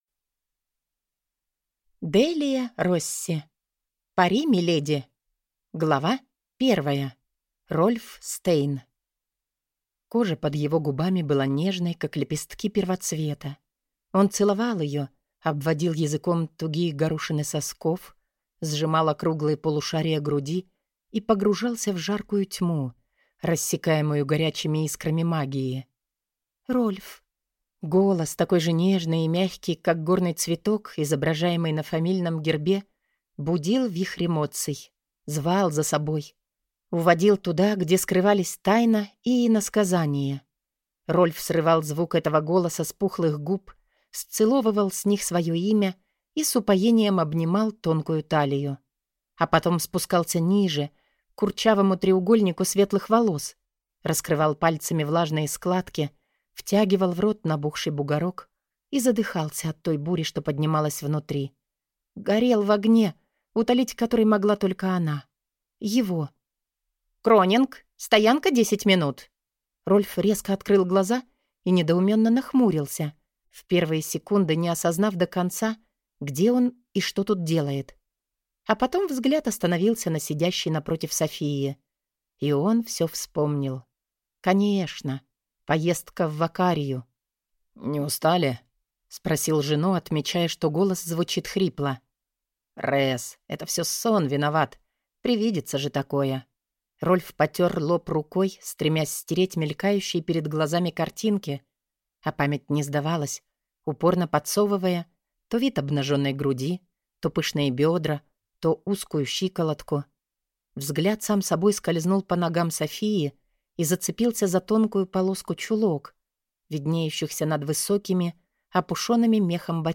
Аудиокнига Пари, миледи?